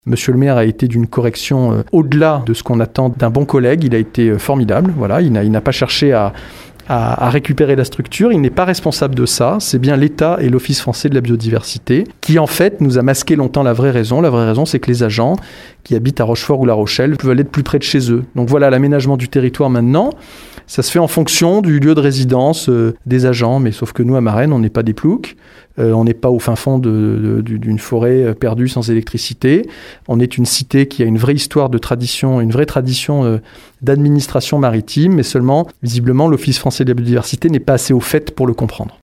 Le sénateur socialiste, qui regrette déjà le départ des 25 agents, nous dévoile la vraie raison qui a conduit à ce transfert, en précisant qu’il ne rejette aucunement la faute sur le maire de droite de Rochefort Hervé Blanché, bien au contraire :